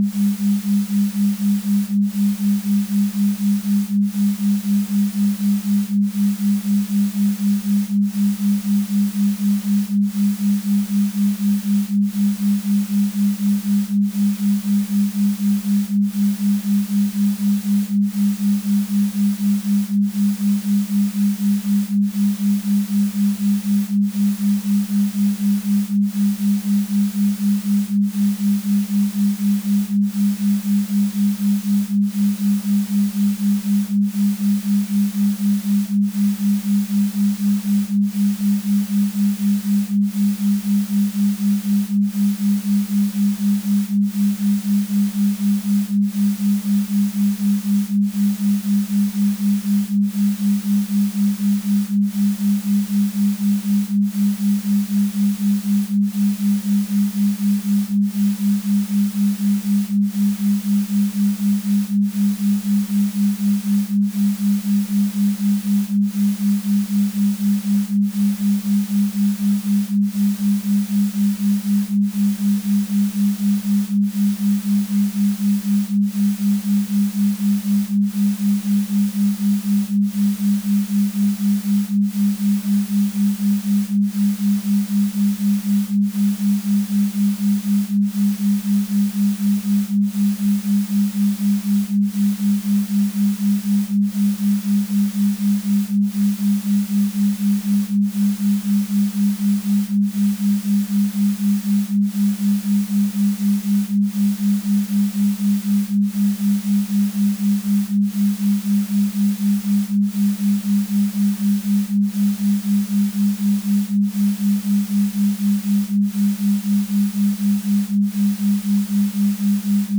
Grief_Comfort_Binaural_Rainfall.wav